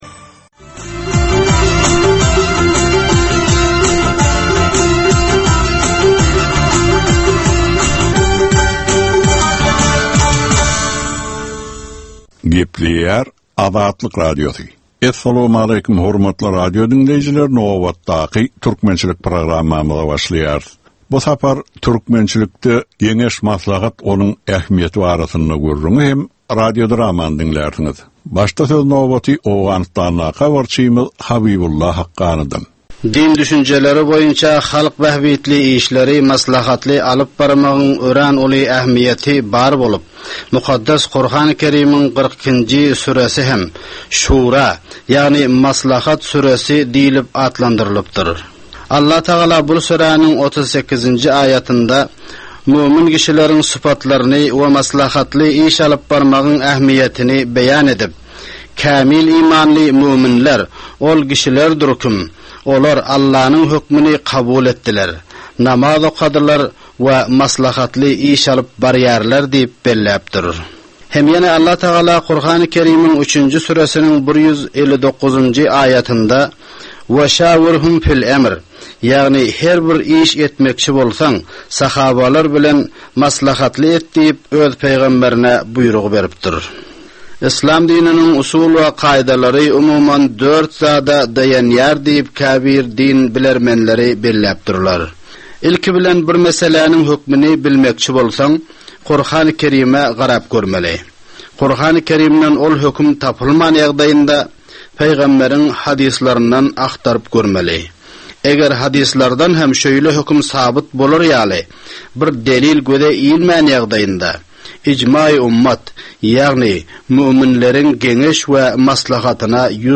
Türkmen halkynyň däp-dessurlary we olaryň dürli meseleleri barada ýörite gepleşik. Bu programmanyň dowamynda türkmen jemgyýetiniň şu günki meseleleri barada taýýarlanylan radio-dramalar hem efire berilýär.